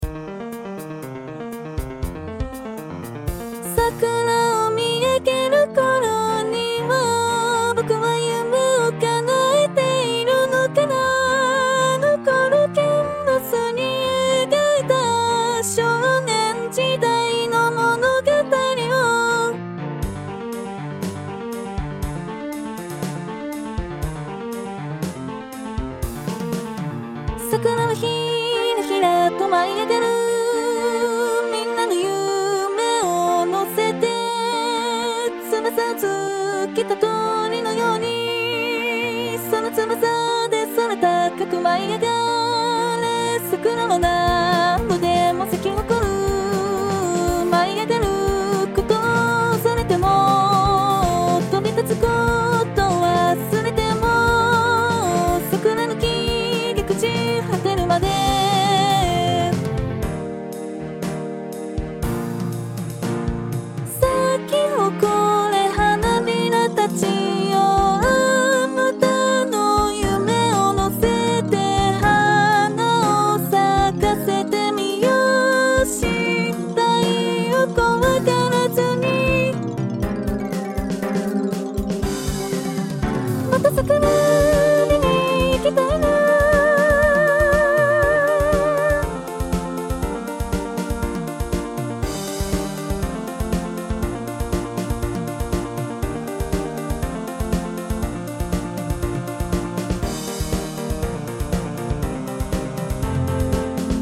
No.01137 [歌]